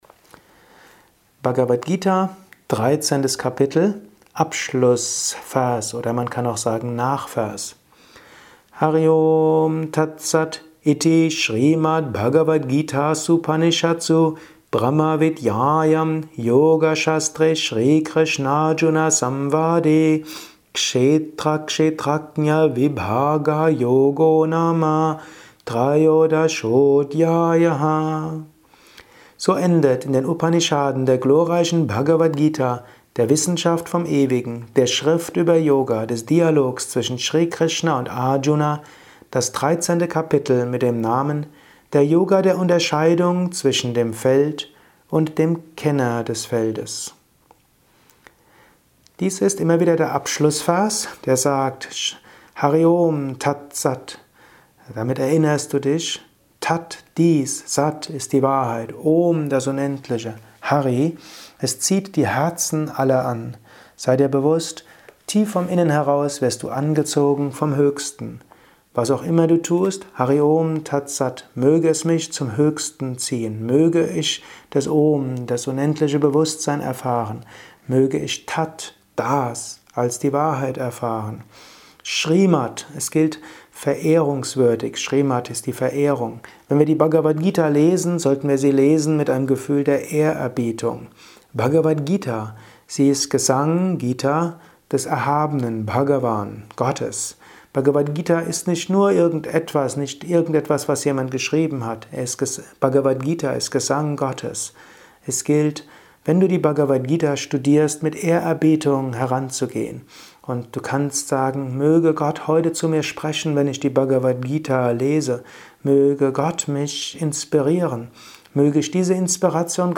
Kurzvorträge
Dies ist ein kurzer Kommentar als Inspiration für den heutigen